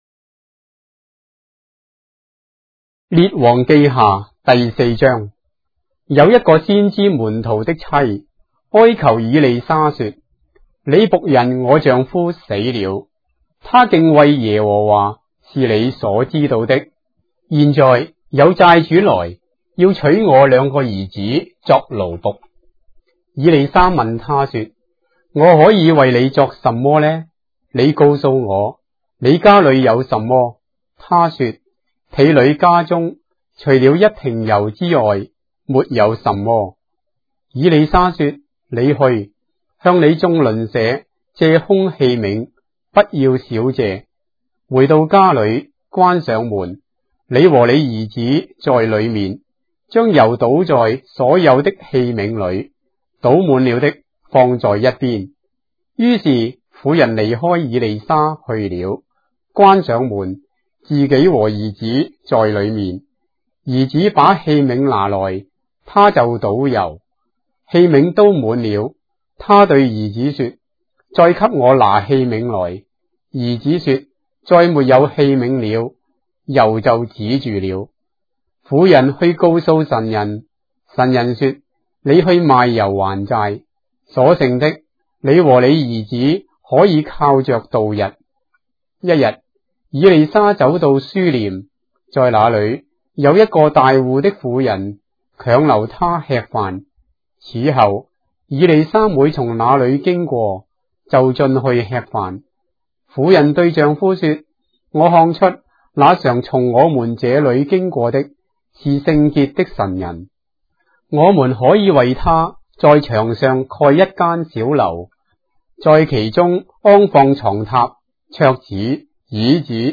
章的聖經在中國的語言，音頻旁白- 2 Kings, chapter 4 of the Holy Bible in Traditional Chinese